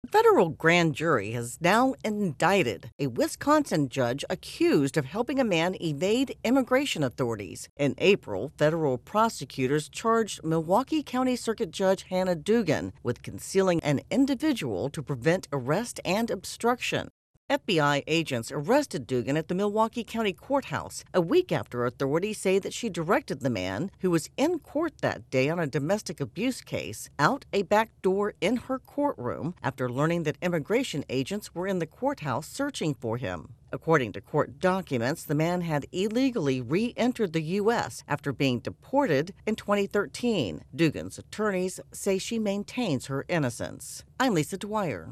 reports on the indictment of a Wisconsin judge.